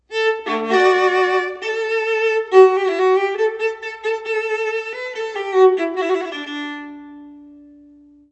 viola.wav